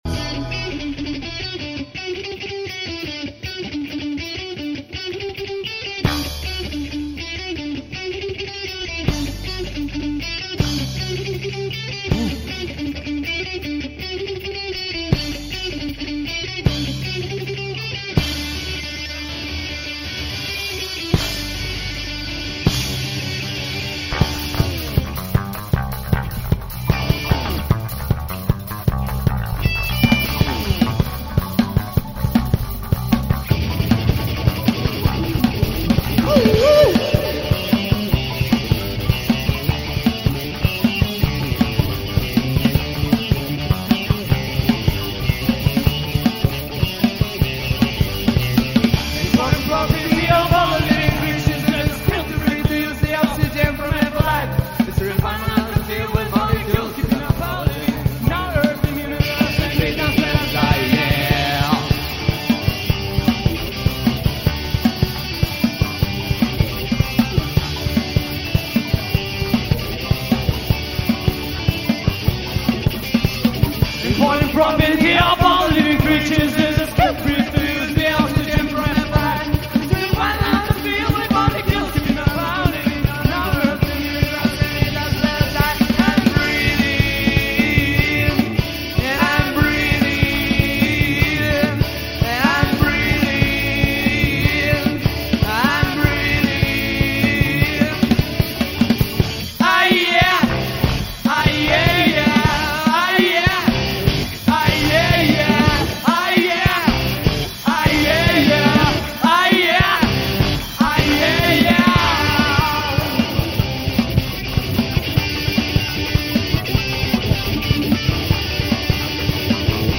LIVE BONUS: